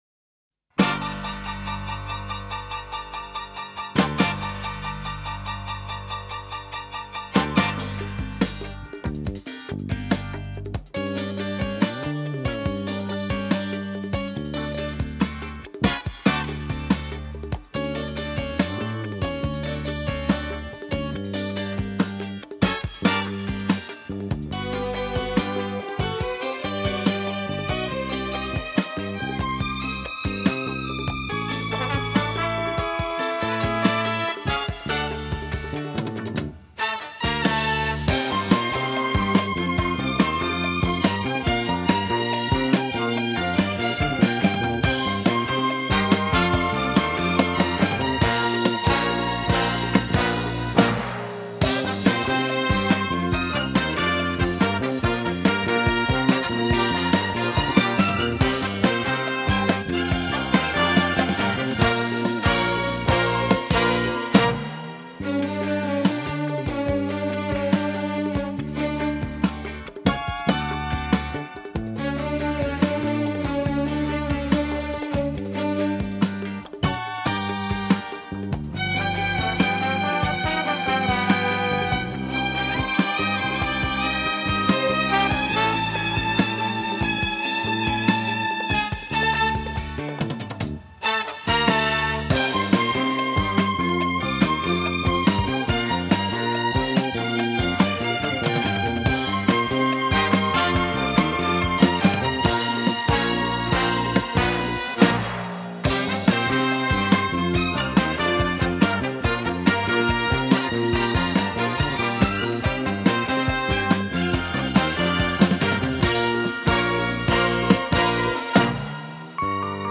А вот ещё инструментальная версия.